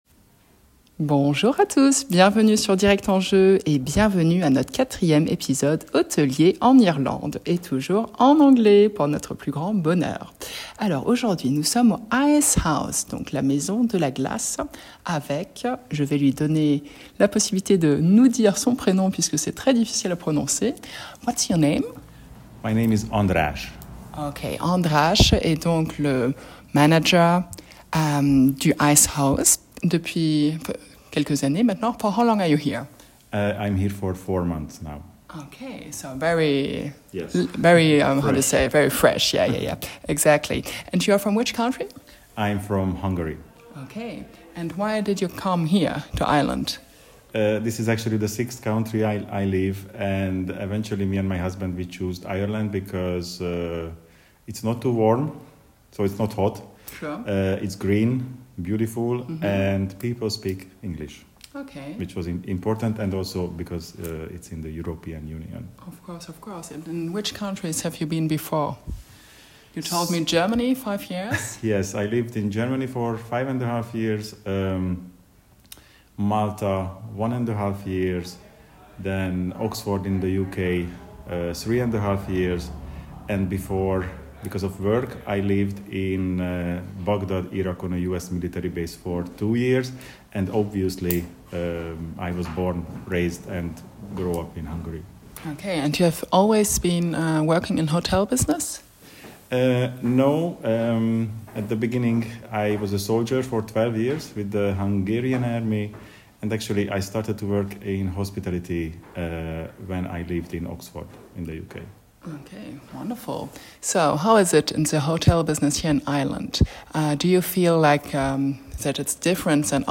ITW en anglais